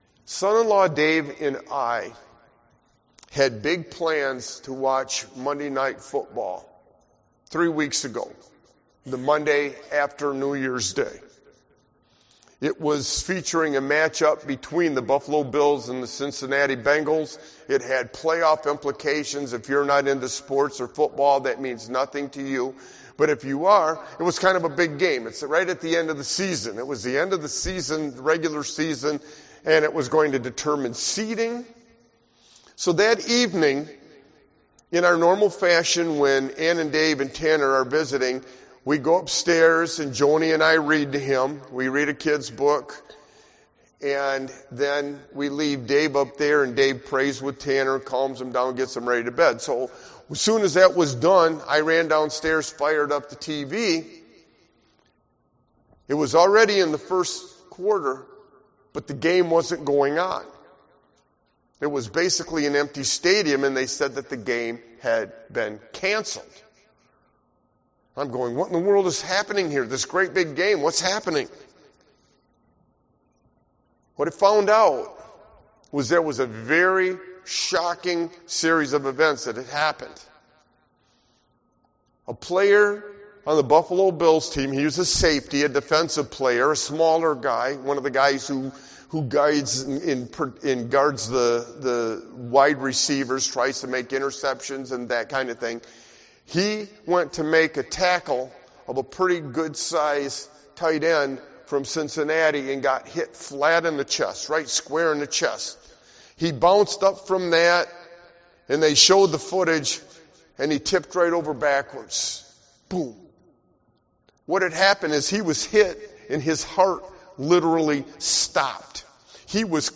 Sermon Walking With Jesus through Difficulty and Loss 3